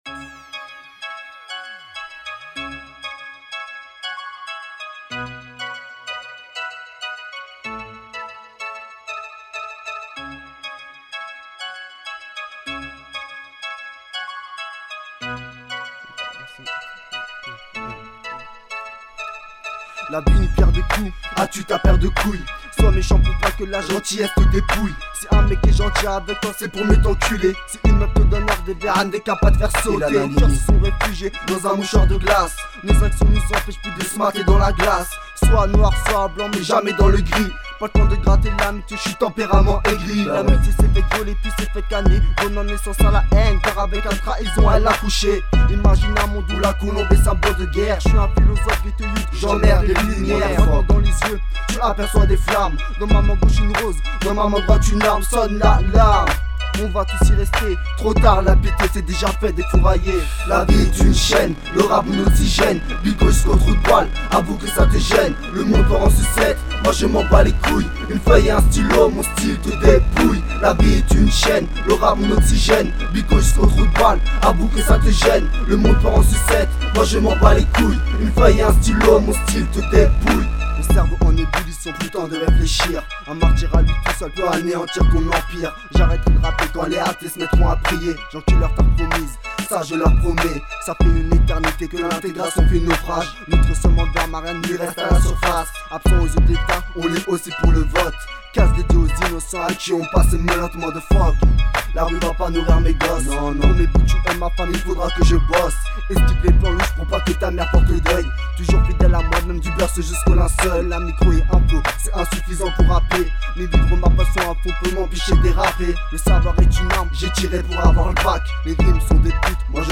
Jeune rappeur conscient issu cette Génération Artistiquement Violente..